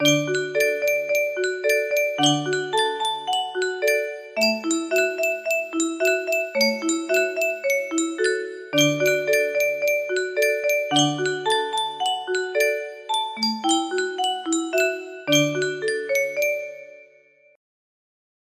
10 little Indian music box melody